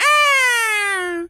bird_vulture_squawk_04.wav